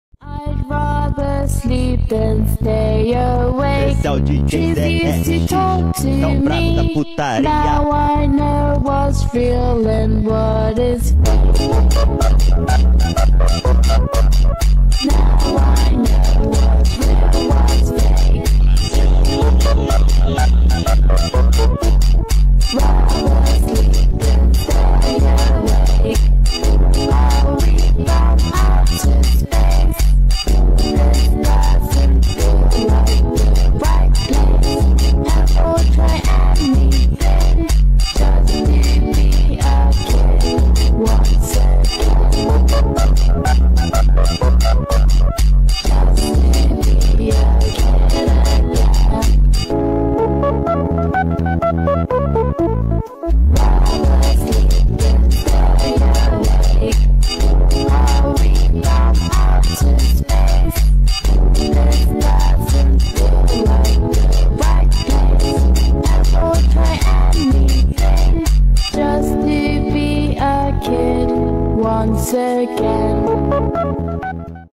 این ترک بیشتر برای ادیت‌های دارک و فانک مناسب است.
فانک